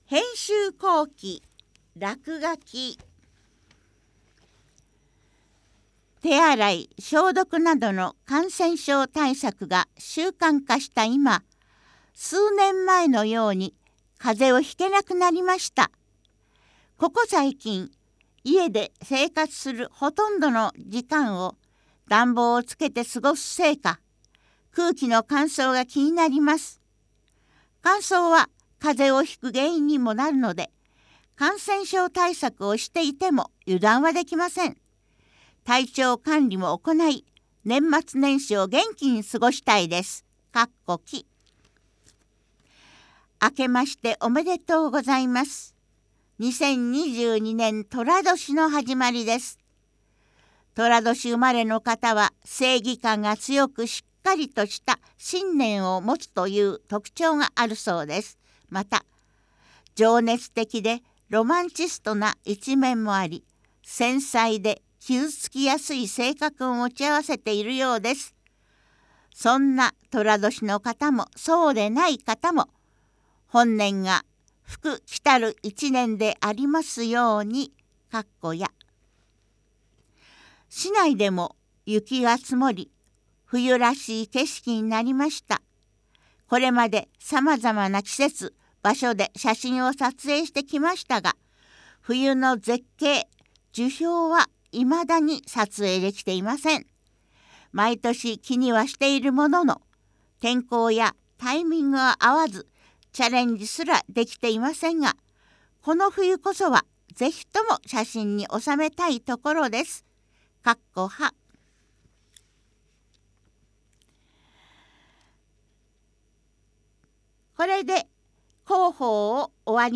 内容をカセットテープに録音し、配布している事業です。
■朗読ボランティア「やまびこ」が音訳しています